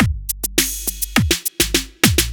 103 BPM Beat Loops Download